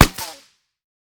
5caee9fba5 Divergent / mods / JSRS Sound Mod / gamedata / sounds / material / bullet / collide / wood01gr.ogg 28 KiB (Stored with Git LFS) Raw History
wood01gr.ogg